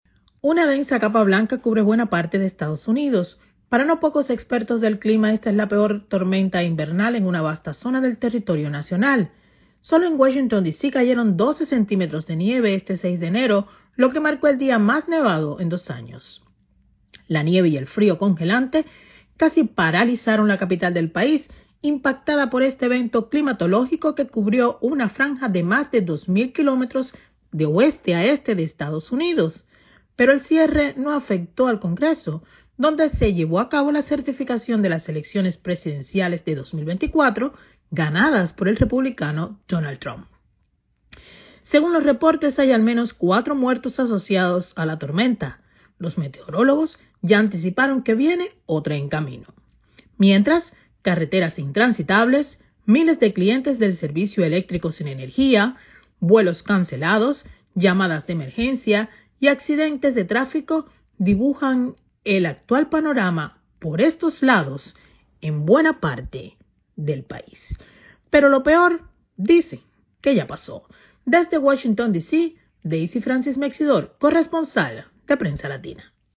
desde Washington DC